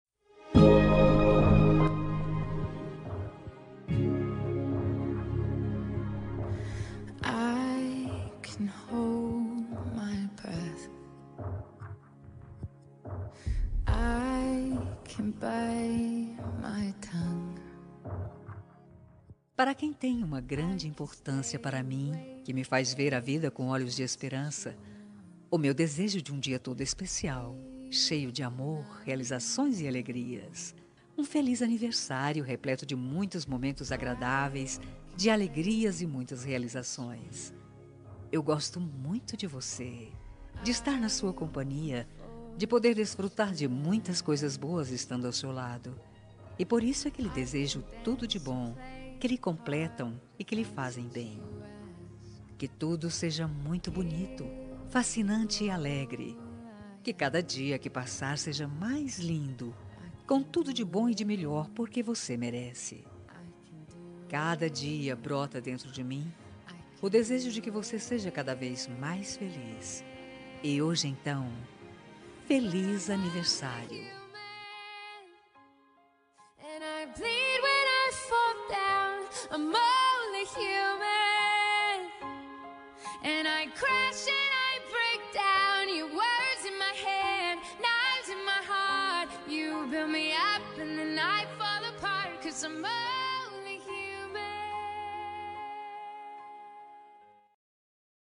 Aniversário de Ficante – Voz Feminina – Cód: 8872
aniv-ficante-fem-8872.m4a